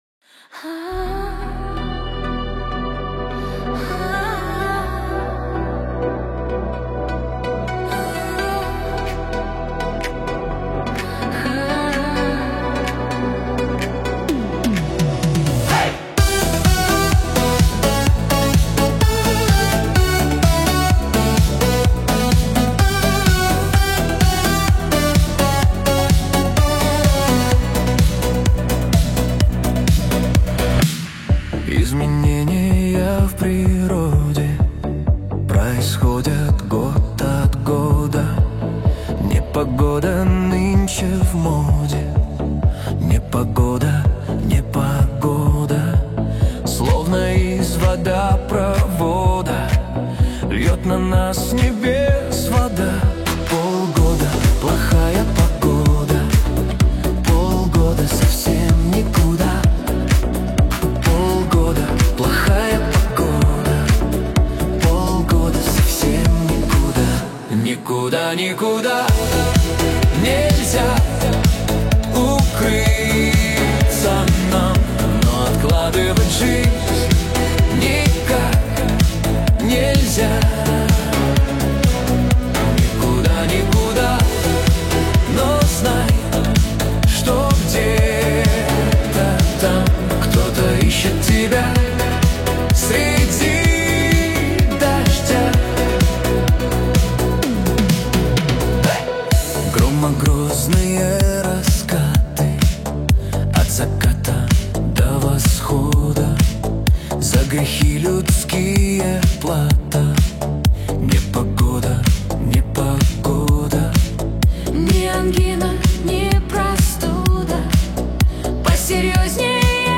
Кавер С Новым Куплетом
Танцевальный Шансон